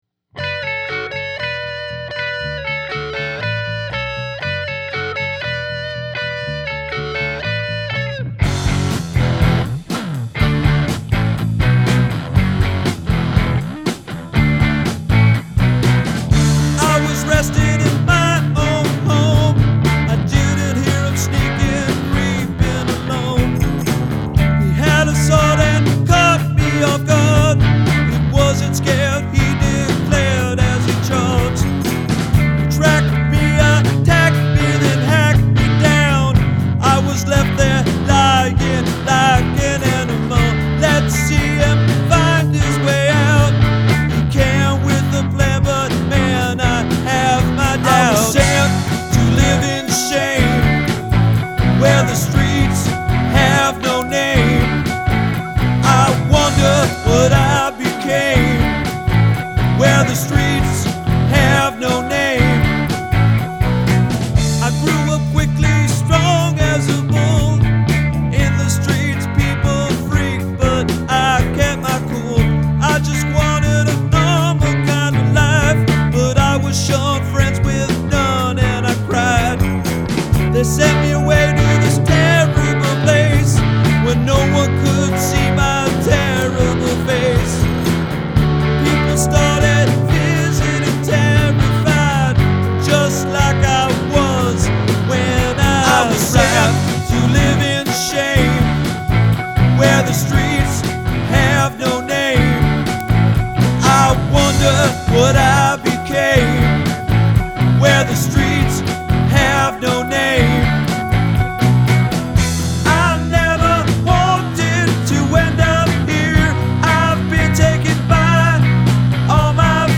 some good layering here, the keyboard stuff adds a lot to the overall vibe and I love it when the main guitar riff comes back in at the end. Lyrics are a good take on the challenge, vocals sound particularly good when they go up into that higher register on the middle 8.
I like the guitarmonies, but I think I hear one of the guitars is clean and the other much more saturated, and while vive la difference, I would've preferred these to be a little bit more similar in texture. After our song this feels really dark in timbre.